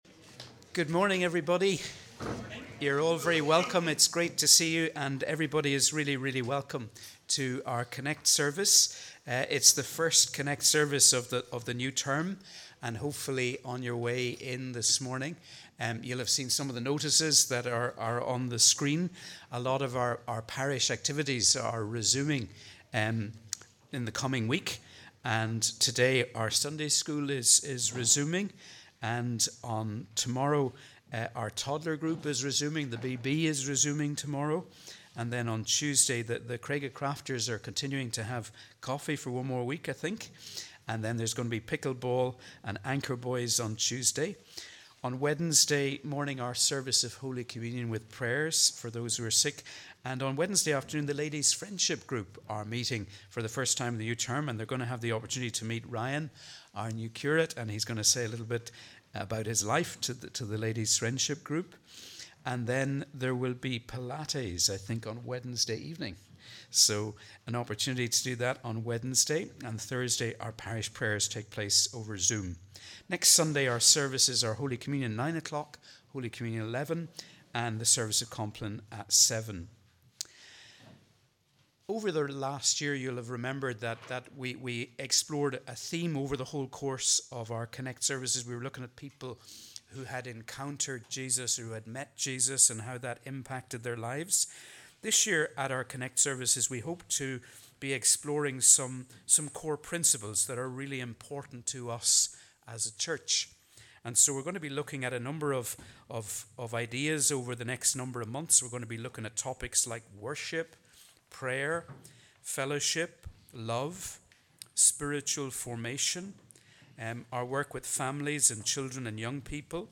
We warmly welcome you to our CONNEC+ service as we worship together on the 12th Sunday after Trinity.